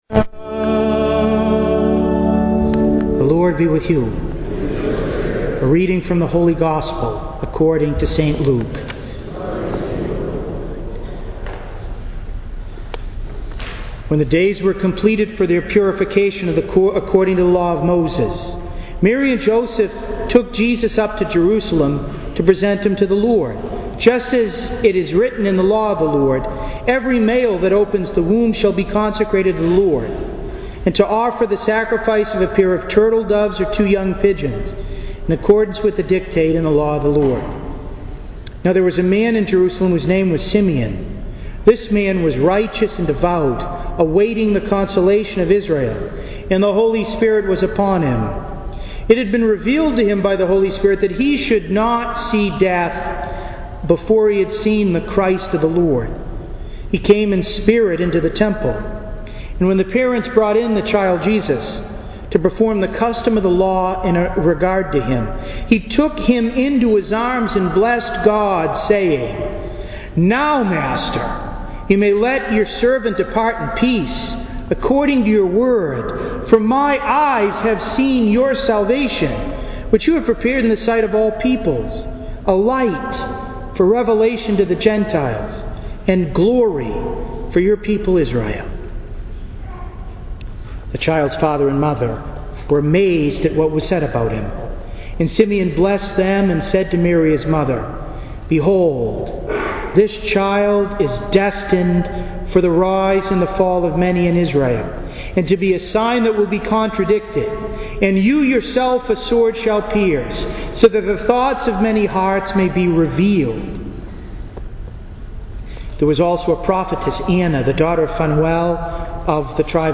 To listen to an audio recording of today’s homily, please click below: